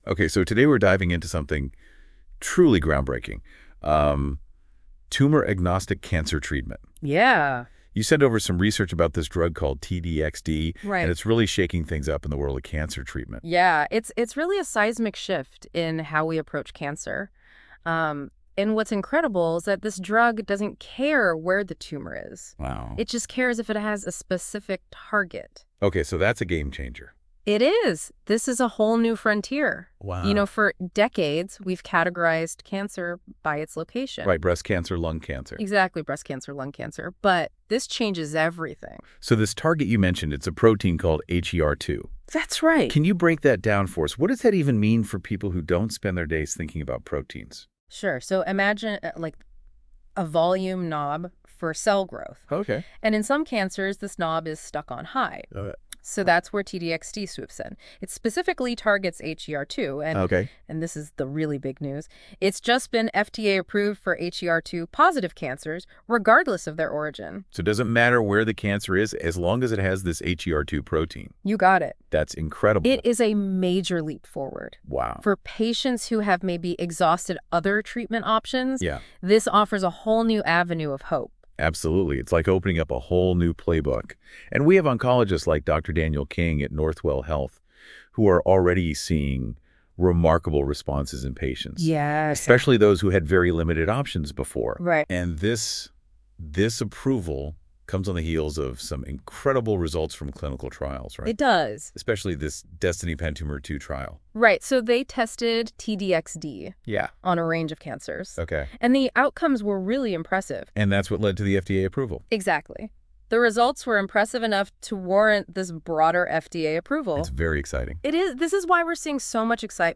Listen to a discussion of this article through our AI-generated podcast.
CAP TODAY now offers the option to listen to a discussion of our articles with voices reminiscent of a Siri or Garmin speaker. In fact, the voices that you will hear are completely AI generated. Since this AI-generated podcast uses technology that is still in its early stages of development, there may be some errors in pronunciation and the tone may sound too upbeat for the material covered.